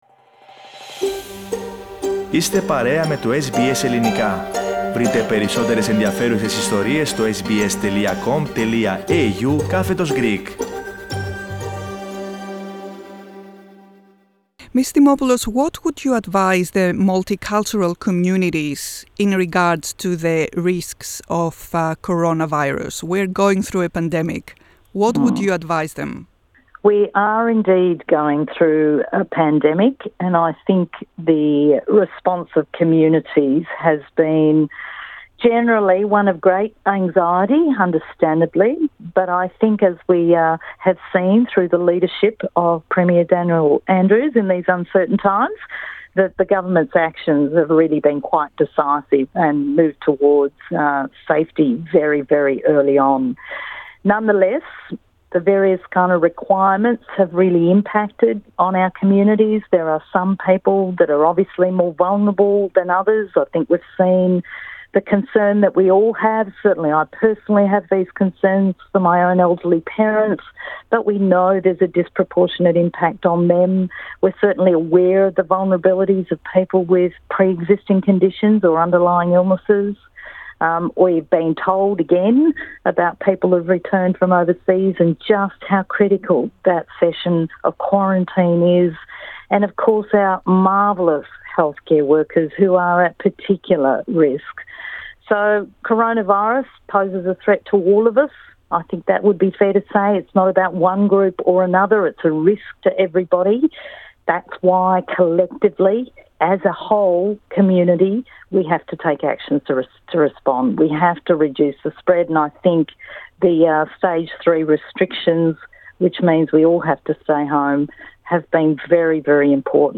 H αντιπρόεδρος της Επιτροπής Πολυπολιτιστικών Υποθέσεων της Βικτώριας Μαρία Δημοπούλου μιλώντας στο SBS Greek για τα περιοριστικά μέτρα και τις εθνοτικές κοινότητες της πολιτείας τόνισε ότι η κοινή προσπάθεια, η συνεργασία και η συλλογικότητα είναι ανάμεσα στους σημαντικότερους παράγοντες για την προστασία της κοινωνίας απ΄τον κορωνοϊό.